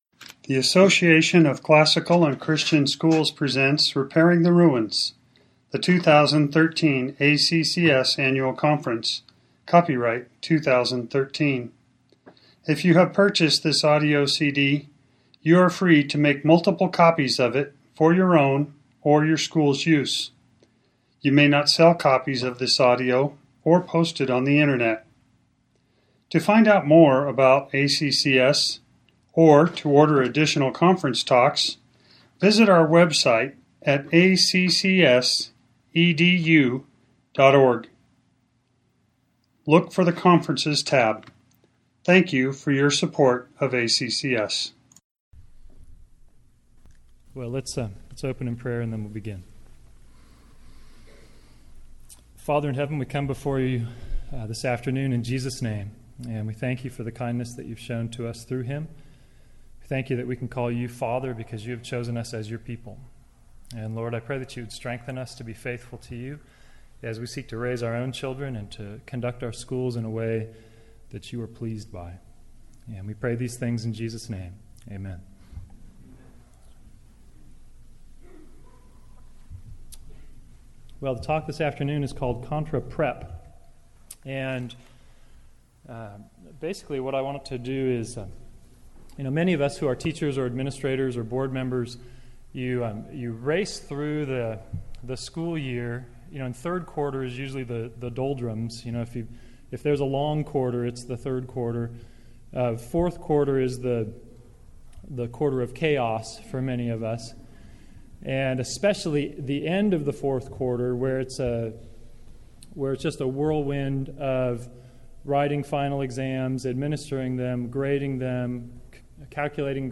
2013 Plenary Talk | 1:04:41 | All Grade Levels, Virtue, Character, Discipline